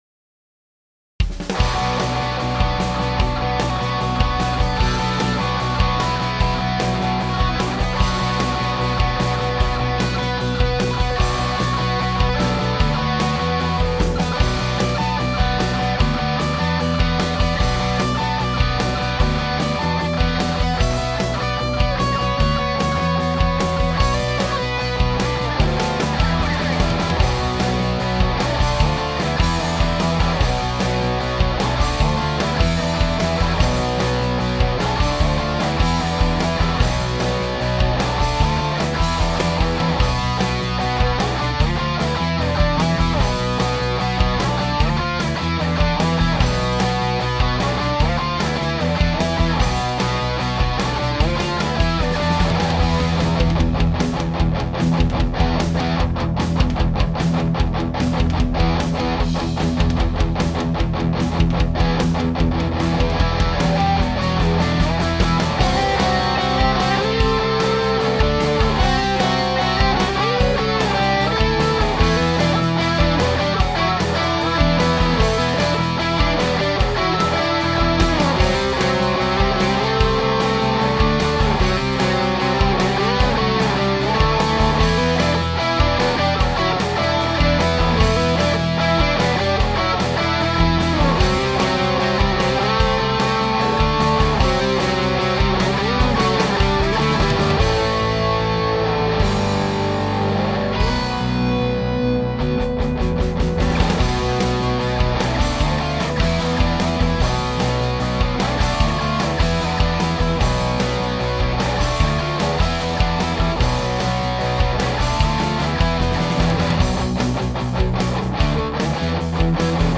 So I moved the Bassman out of the closet and into my (obnoxiously) large bedroom.
I have the amp facing the direction of the larger ceiling height and near the middle of the room. Everything else is the same, including the rhythm track.
It certainly sounds less boxy to me.